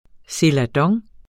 Udtale [ selaˈdʌŋ ]